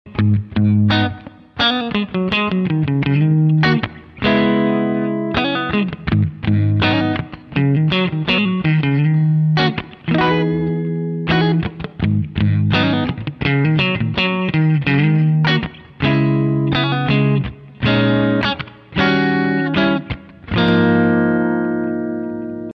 All the audio is from my new vox amplug3 US Silver.